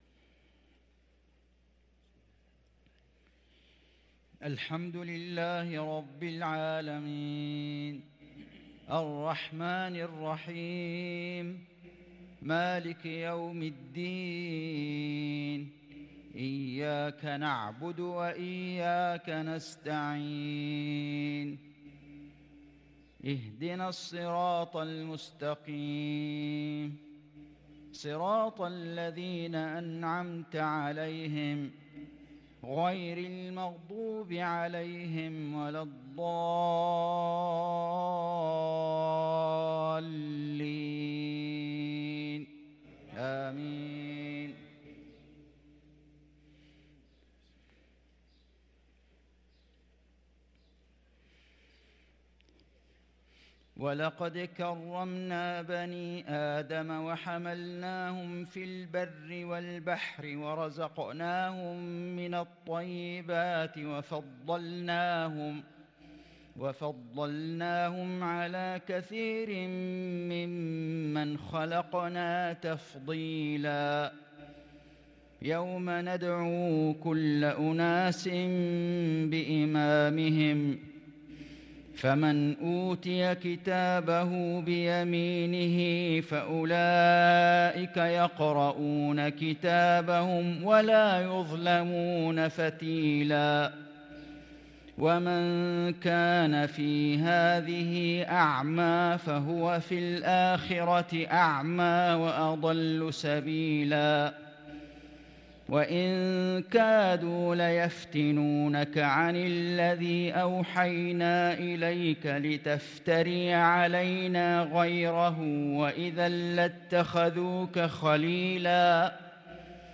صلاة العشاء للقارئ فيصل غزاوي 16 جمادي الآخر 1444 هـ
تِلَاوَات الْحَرَمَيْن .